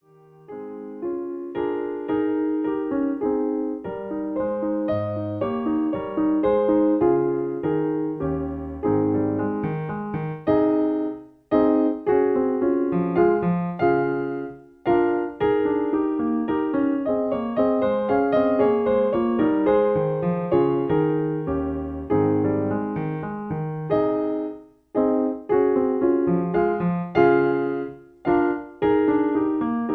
In A-flat. Piano Accompaniment